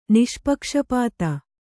♪ niṣpakṣapāta